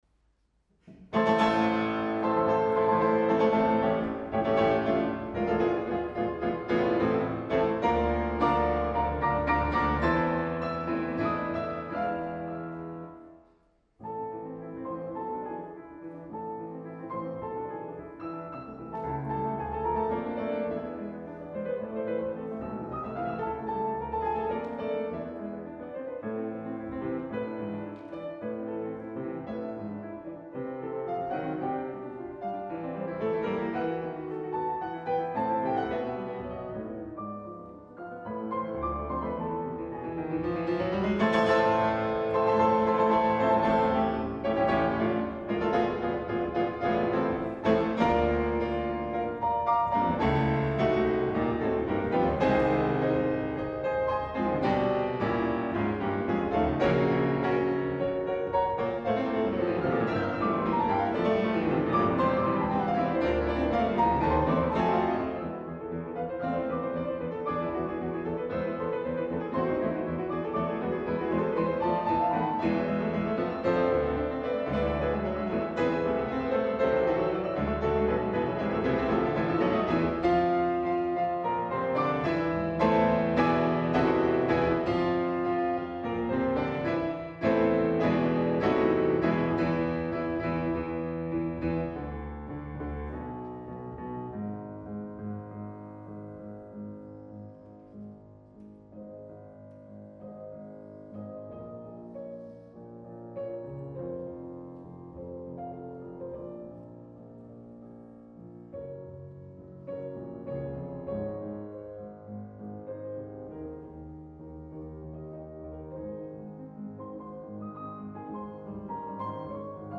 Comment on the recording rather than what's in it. Venue: St. Brendan’s Church